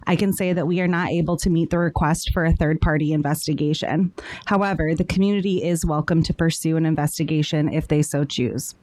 Board of Education member Megan Maddock says they consulted with the attorney from the Michigan Association of School Boards, who confirmed their hands were tied by confidentiality clauses that Dr. Richoudhuri included in her separation deal.
meganprobe.mp3